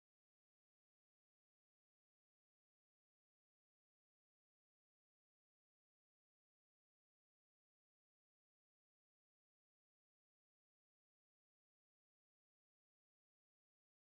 Spielverse
Tonart: F-Dur
Taktart: 2/4
Tonumfang: Oktave